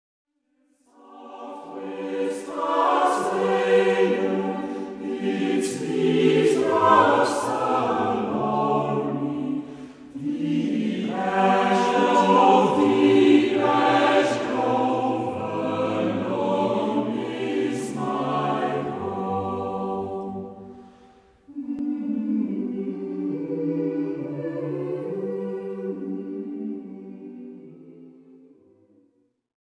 SATB (4 voix mixtes) ; Partition complète.
Tonalité : sol majeur